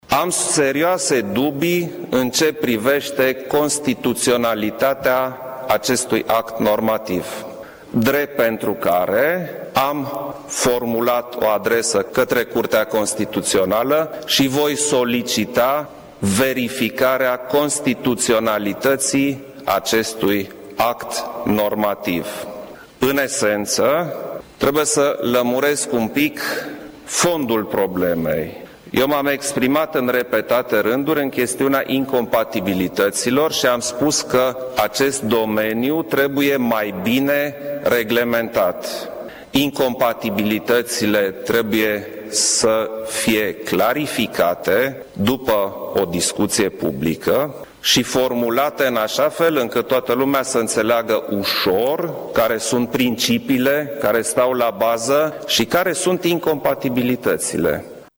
Preşedintele României a anunțat cu puțin timp în urmă, într-o conferință de presă, că nu va promulga un proiect legislativ aprobat deja de Parlament,pentru modificarea Legii serviciilor comunitare de utilităţi publice (Legea 51/2006), care prevede ca primarii şi preşedintii de CJ pot fi membri în Adunarea generală a acţionarilor.